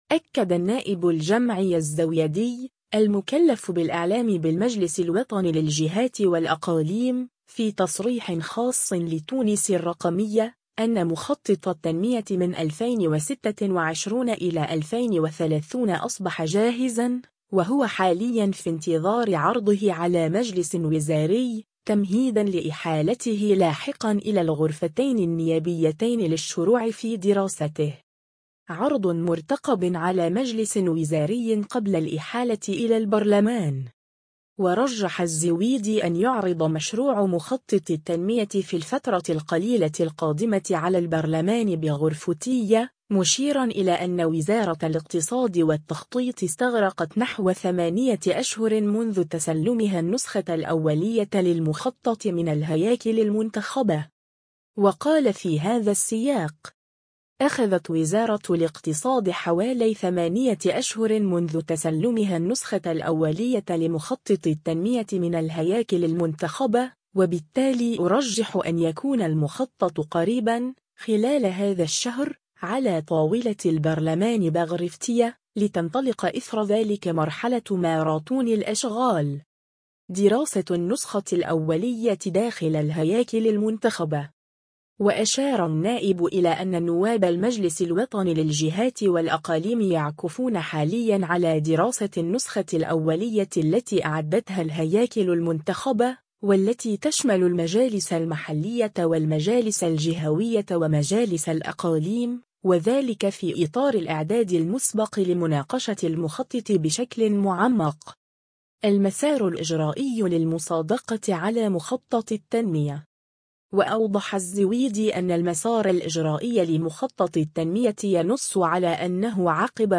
أكد النائب الجمعي الزويدي، المكلف بالإعلام بالمجلس الوطني للجهات والأقاليم، في تصريح خاص لـ”تونس الرقمية”، أن مخطط التنمية 2026–2030 أصبح جاهزًا، وهو حاليًا في انتظار عرضه على مجلس وزاري، تمهيدًا لإحالته لاحقًا إلى الغرفتين النيابيتين للشروع في دراسته.